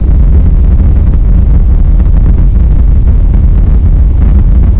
rumbleloop.wav